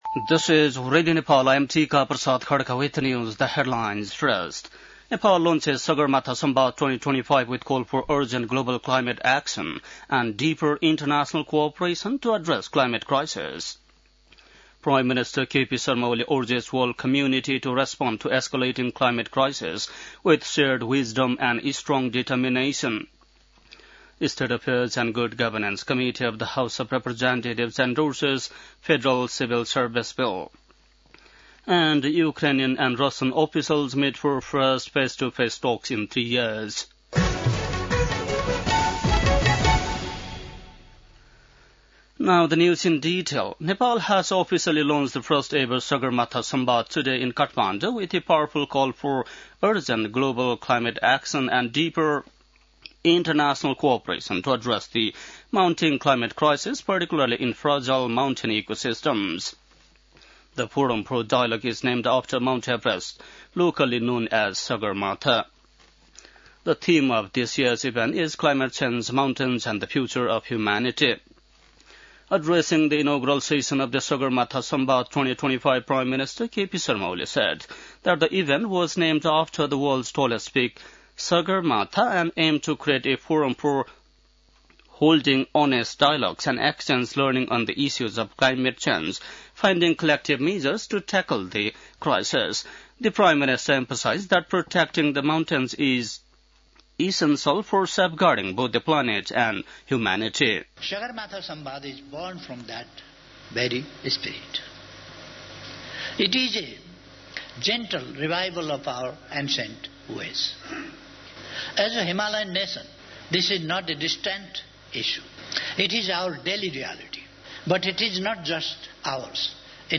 बेलुकी ८ बजेको अङ्ग्रेजी समाचार : २ जेठ , २०८२
8-pm-english-news-02-2.mp3